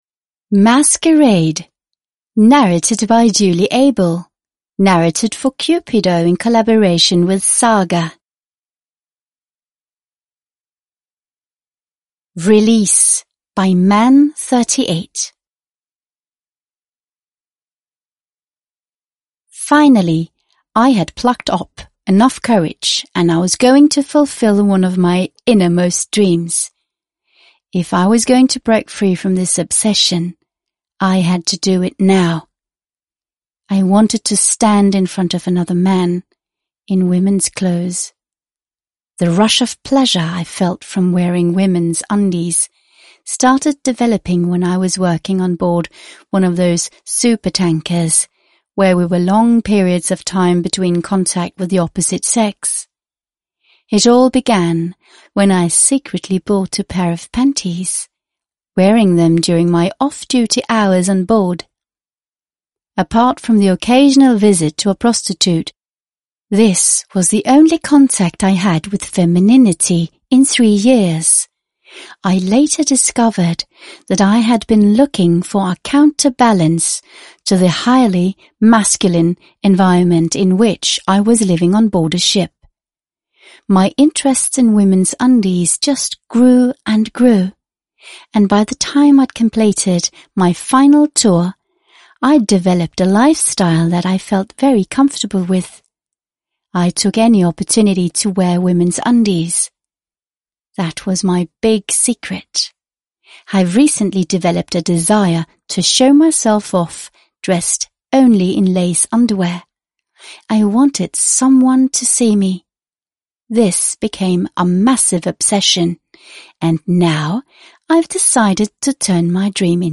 Masquerade (ljudbok) av Cupido